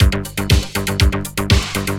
По началу мне казалось что это Bass DX (Yamaha DX7)
bass (отрывок).wav